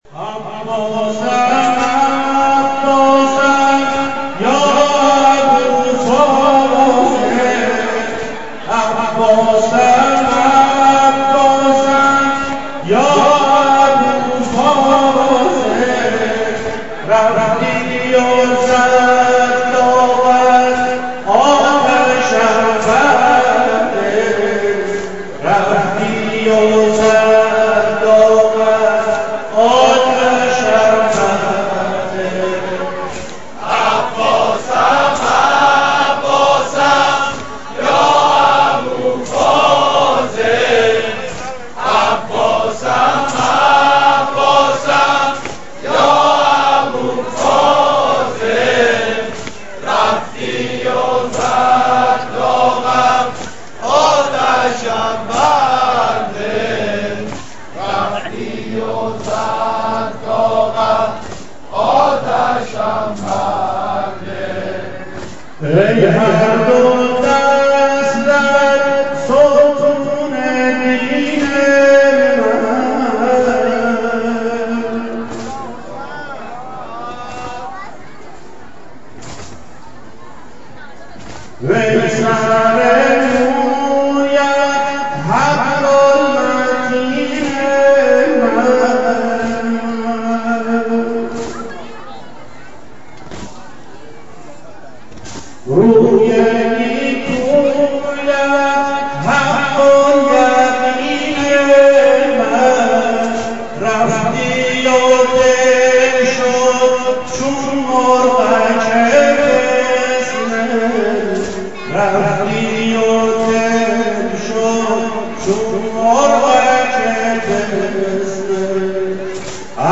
در دل شب‌های محرم، نغمه‌هایی بودند که با سوز دل مداحان قدیمی، راه دل را به کربلا باز می‌کردند.
نوحه‌خوانی